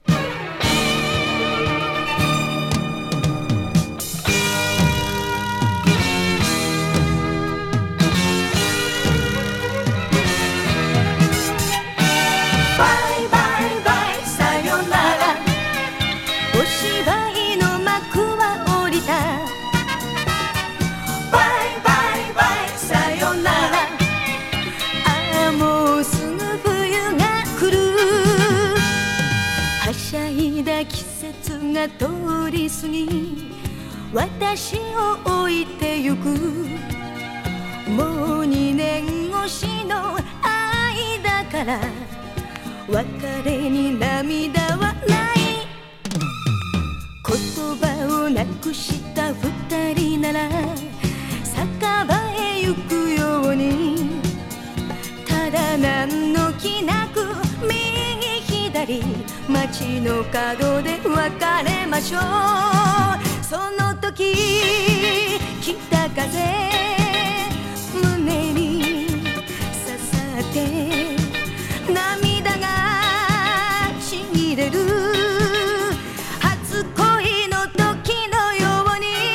グルーヴィー歌謡好きに！